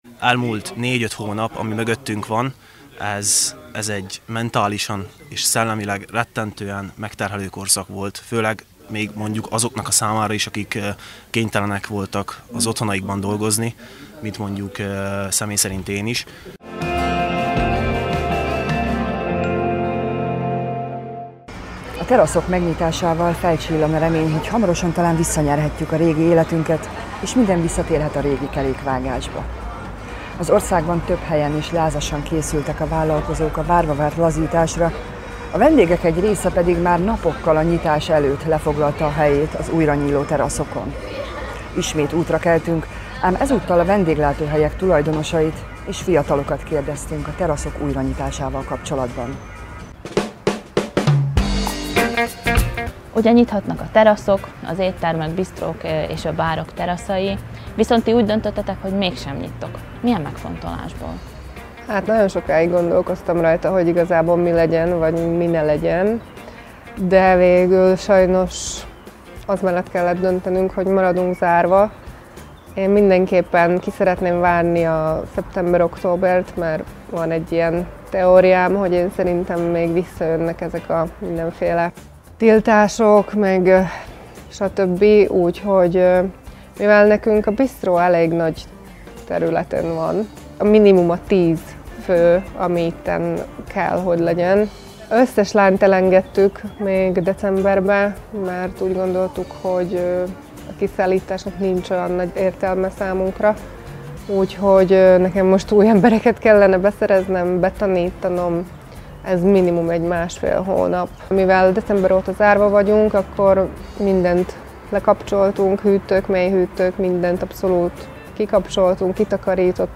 Ismét útra keltünk, ám ezúttal mátyusföldi és csallóközi vendéglátóhelyek tulajdonosait és környékbeli fiatalokat kérdeztünk a teraszok újranyitásával kapcsolatban.